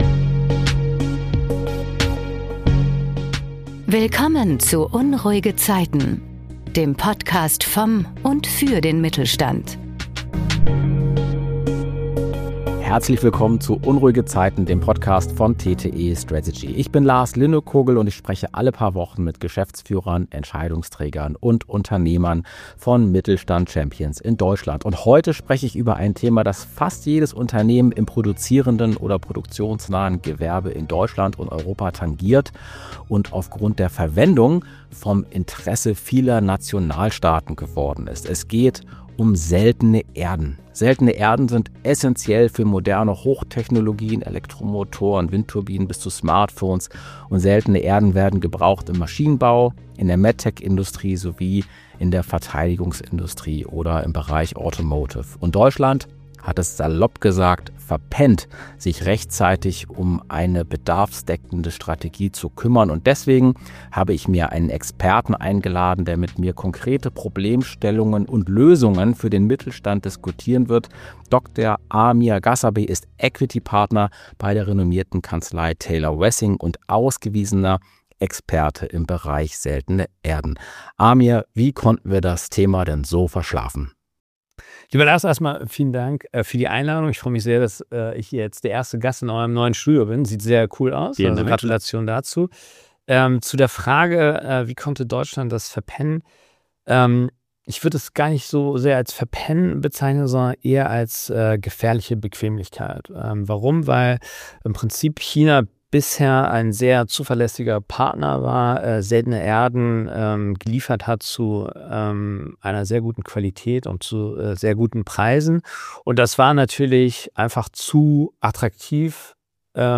Für mehr interessante Geschichten und Interviews aus dem Mittelstand: Jetzt den Podcast abonnieren.